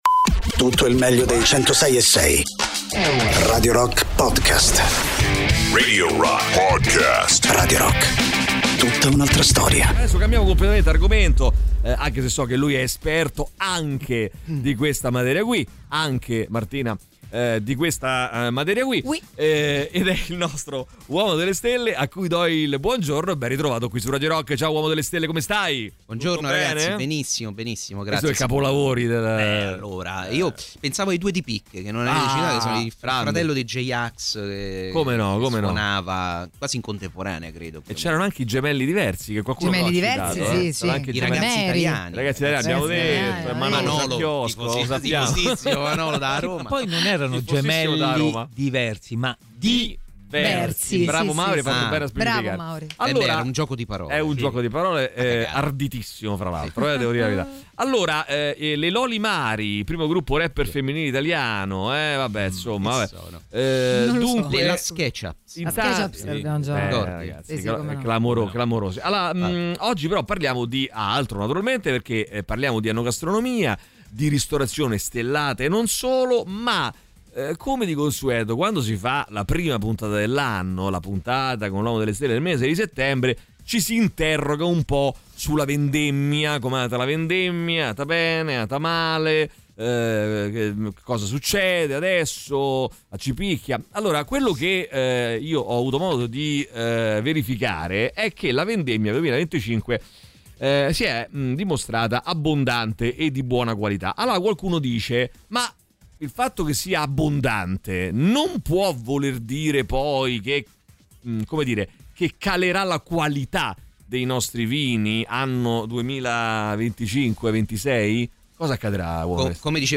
sui 106.6 di Radio Rock